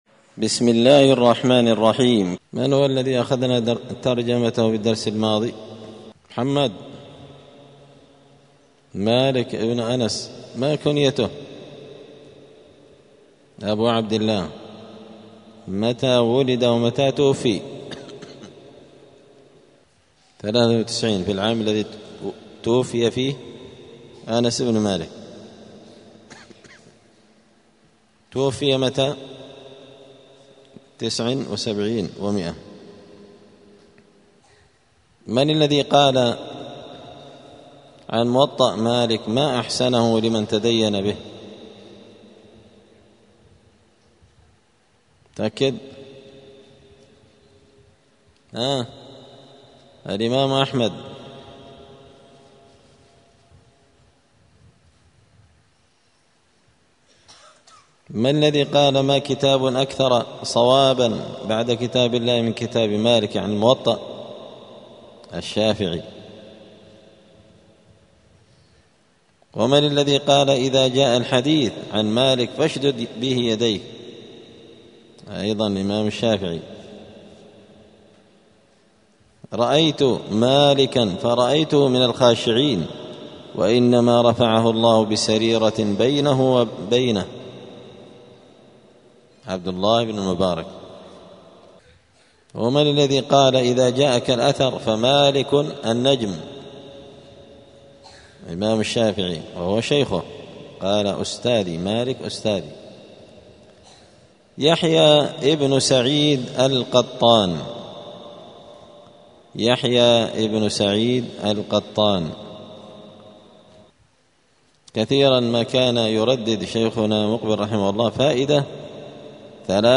الخميس 21 ربيع الثاني 1446 هــــ | الدروس، المحرر في الجرح والتعديل، دروس الحديث وعلومه | شارك بتعليقك | 21 المشاهدات
دار الحديث السلفية بمسجد الفرقان قشن المهرة اليمن